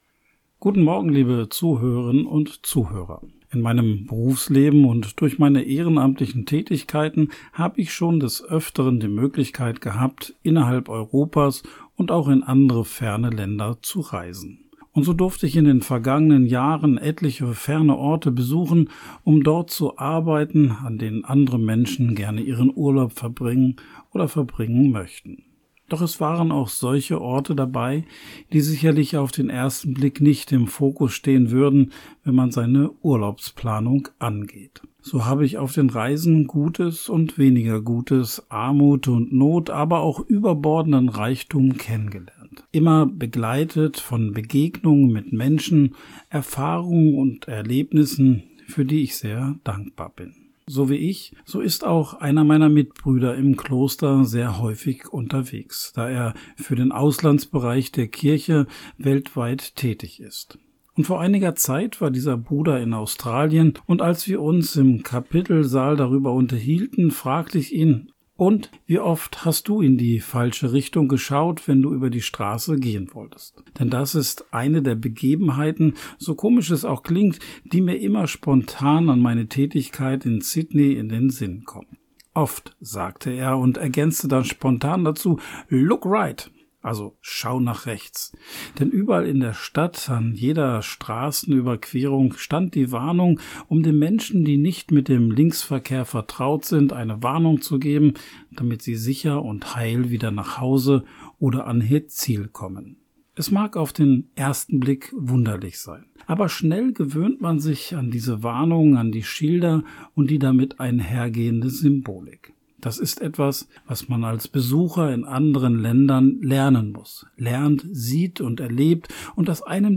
Radioandacht vom 13. Februar